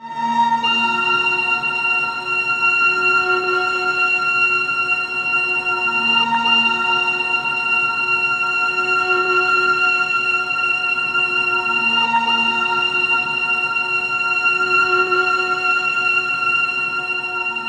095 Bond String-A#.wav